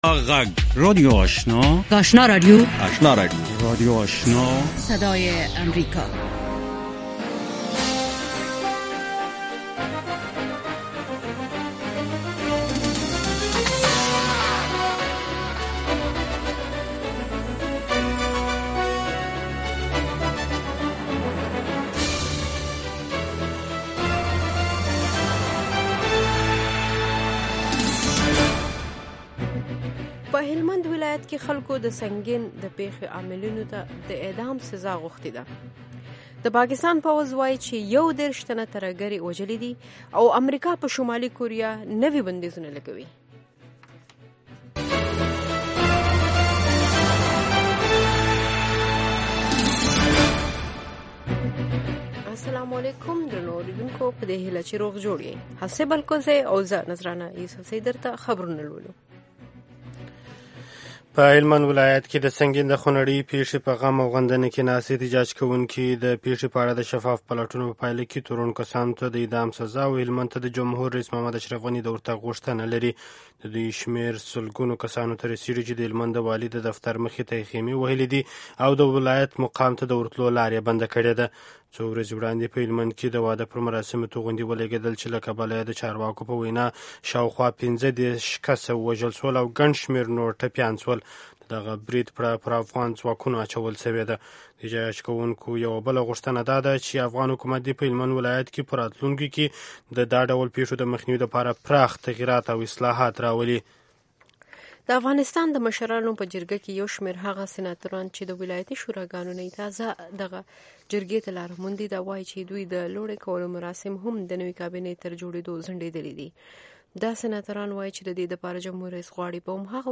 یو ساعته پروگرام: تازه خبرونه، او د نن شپې تېر شوي پروگرامونه په ثبت شوي بڼه، هنري، علمي او ادبي مسایلو په اړه د شعر، ادب او بیلا بیلو هنرونو له وتلو څیرو سره.